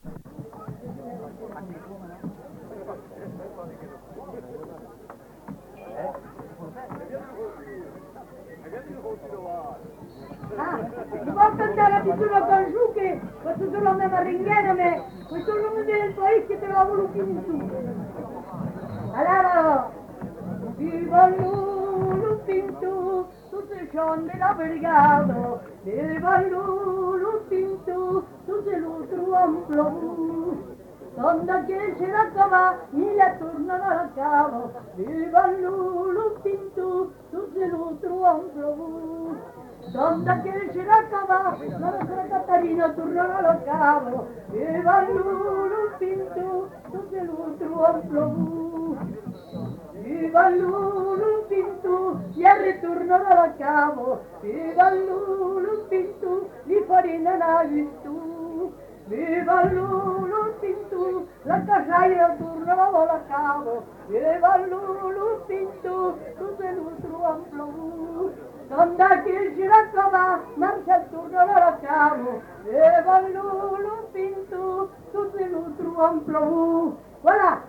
Lieu : Pons
Genre : chant
Effectif : 1
Type de voix : voix de femme
Production du son : chanté